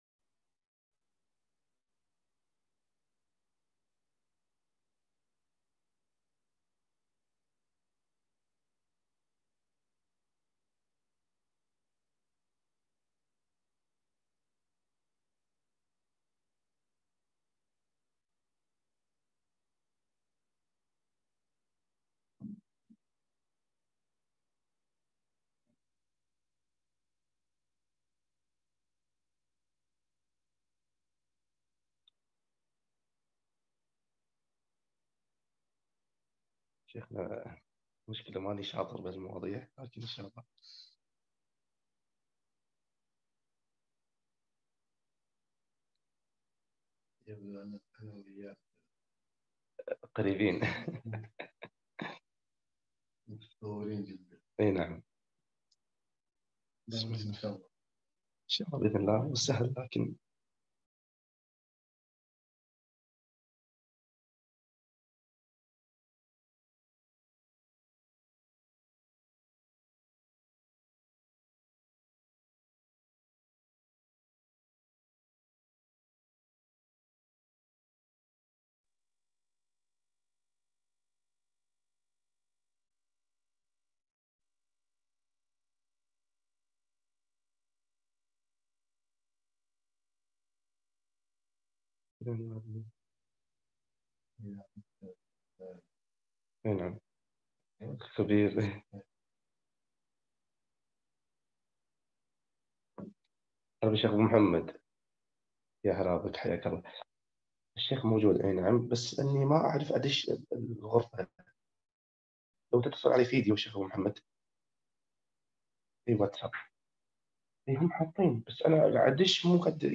محاضرة - مالا يسع المرأة المسلمة جهله في العقيدة - الجزء الثاني